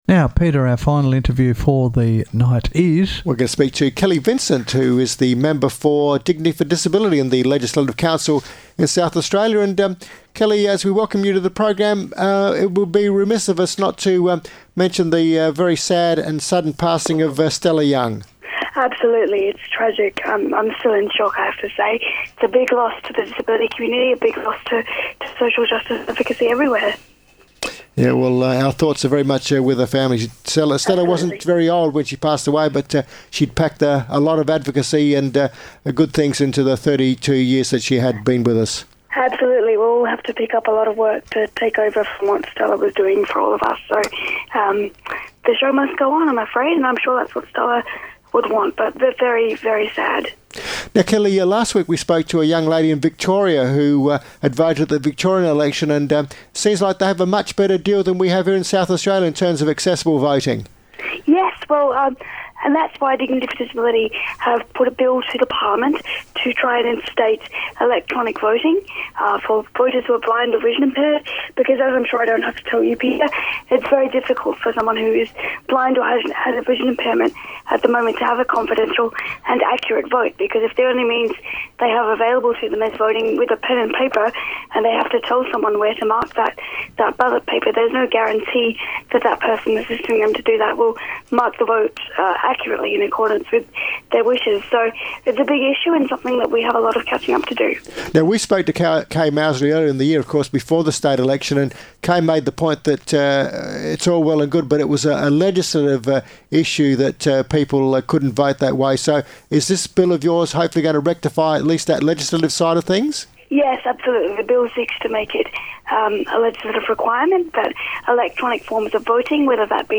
Kelly Vincent radio interview on 1197am RPH 10/12/2014 regarding access to voting for people with disabilities